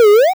bubble.wav